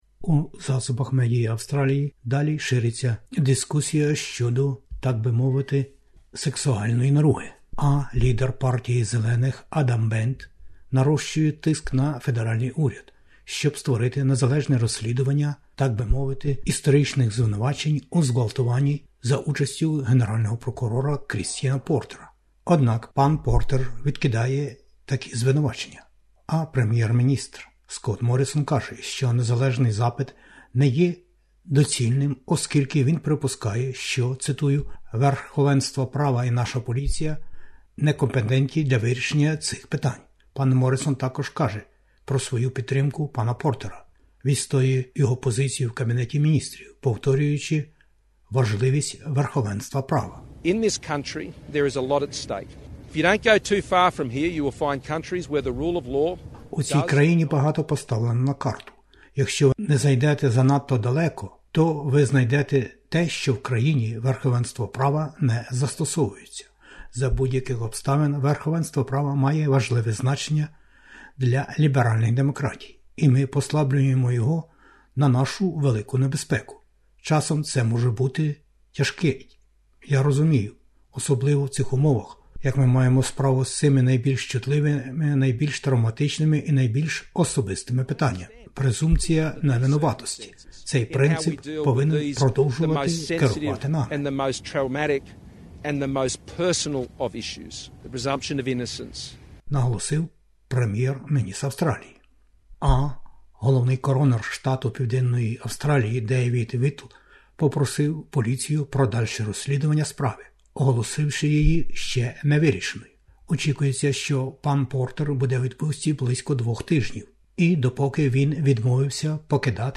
Вістки СБС українською мовою про найважливіше в Австралії, Україні та світі.